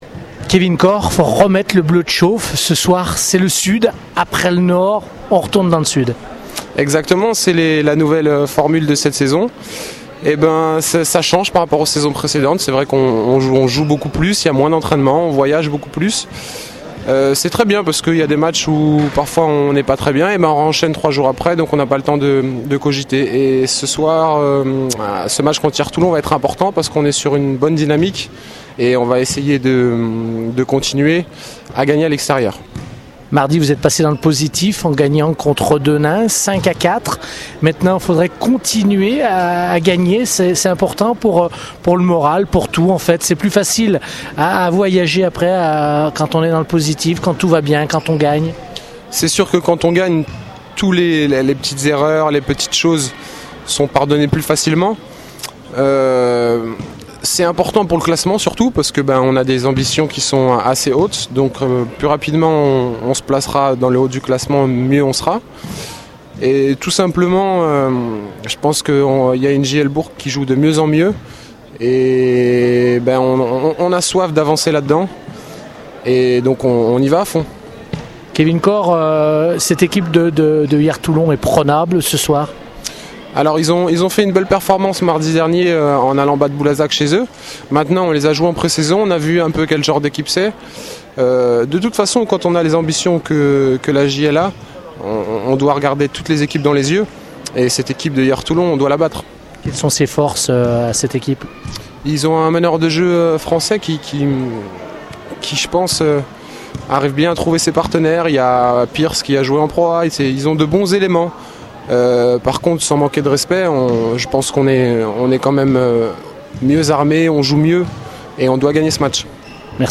parlent de ce match au micro Radio Scoop :